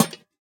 Minecraft Version Minecraft Version snapshot Latest Release | Latest Snapshot snapshot / assets / minecraft / sounds / block / lantern / place6.ogg Compare With Compare With Latest Release | Latest Snapshot